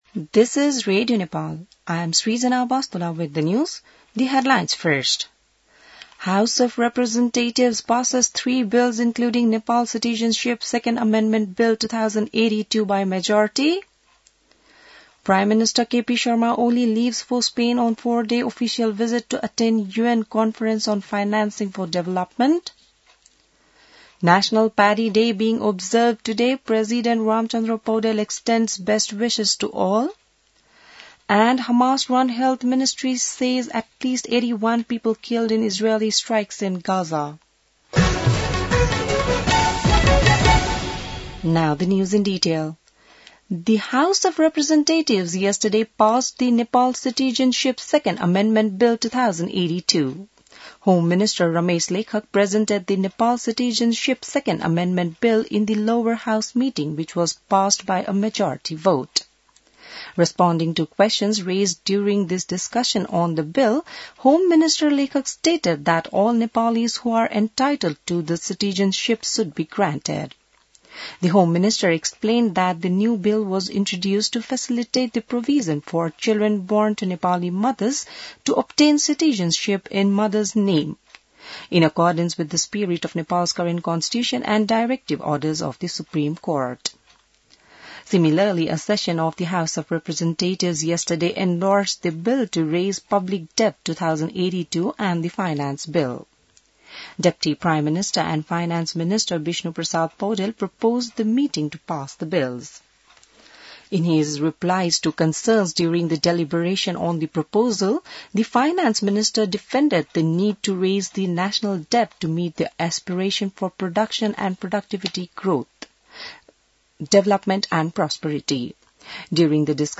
बिहान ८ बजेको अङ्ग्रेजी समाचार : १५ असार , २०८२